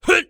ZS普通攻击4.wav
ZS普通攻击4.wav 0:00.00 0:00.32 ZS普通攻击4.wav WAV · 27 KB · 單聲道 (1ch) 下载文件 本站所有音效均采用 CC0 授权 ，可免费用于商业与个人项目，无需署名。
人声采集素材/男3战士型/ZS普通攻击4.wav